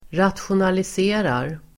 Uttal: [ratsjonalis'e:rar]